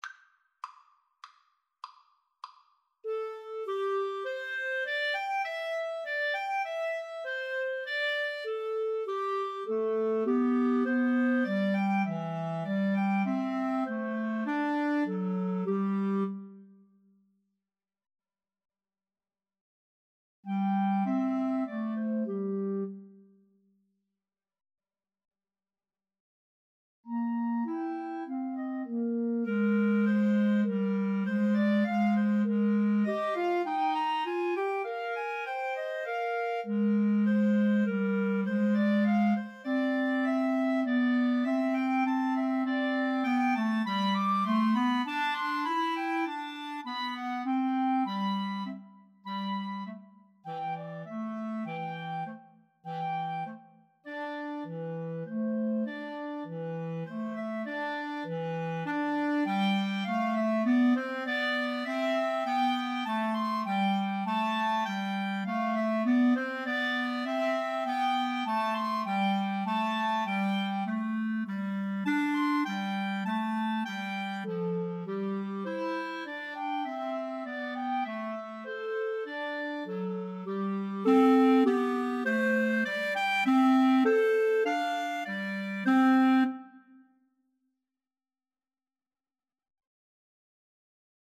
C major (Sounding Pitch) D major (Clarinet in Bb) (View more C major Music for Clarinet Trio )
5/4 (View more 5/4 Music)
Allegro guisto (View more music marked Allegro)
Clarinet Trio  (View more Easy Clarinet Trio Music)
Classical (View more Classical Clarinet Trio Music)